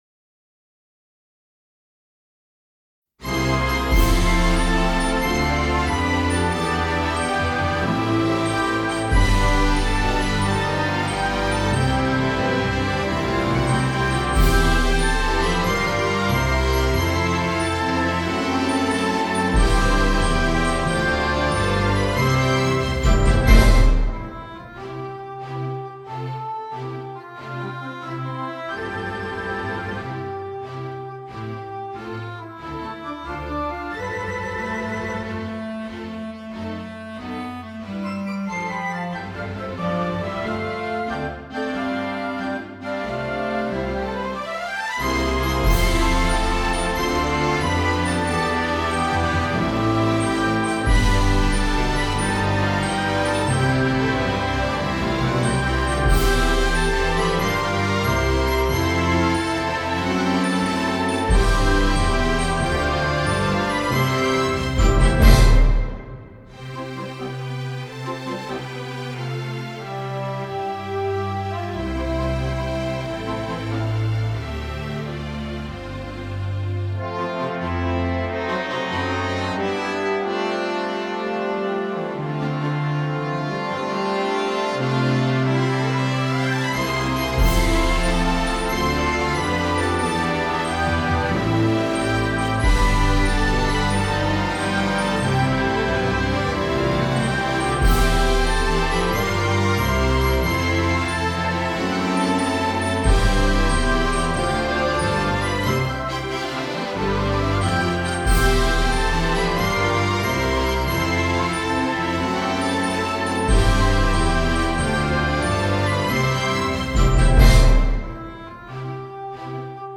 Hino a Rolante - instrumental.